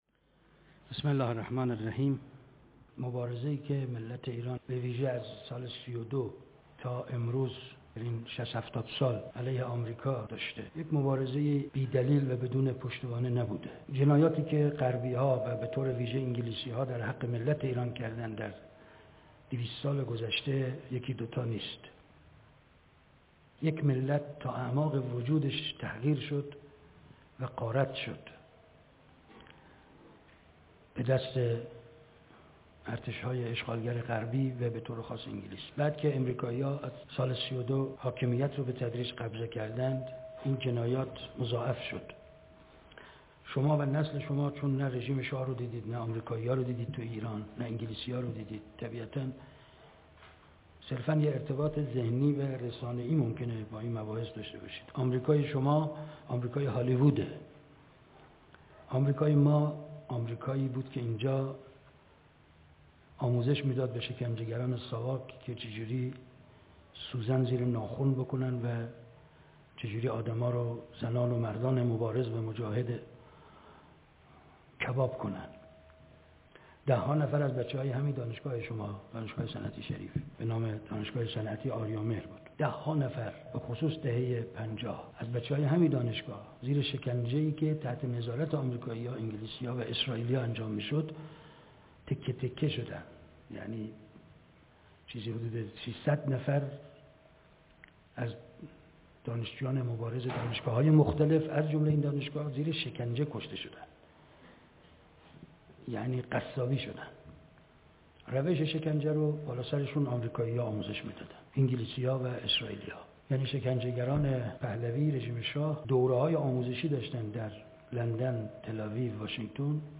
دانشگاه صنعتی شریف _ روز مبارزه بااستکبار _ ۱۳ آبان ۱۳۹۸